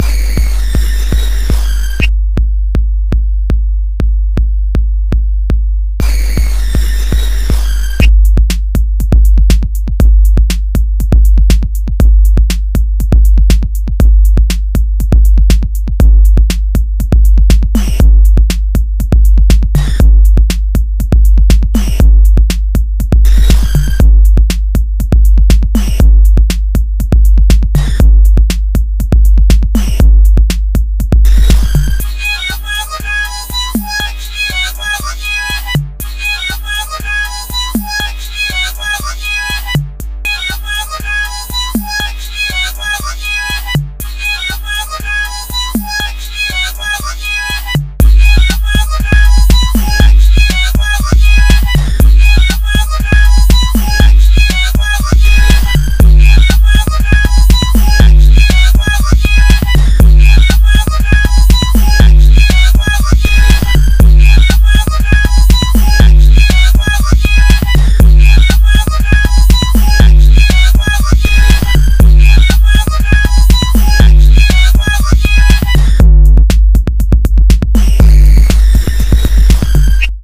사운드를 다 벗겨냈습니당.
베이스의 역할이라 중요하진 않고요.
더 하기 귀찮아서 매우 짧습니다 확정되면 쓸 예정이에요